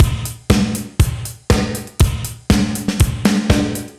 AM_GateDrums_120-02.wav